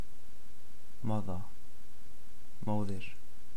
English word Icelandic word Spoken comparison